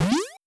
Jump2.wav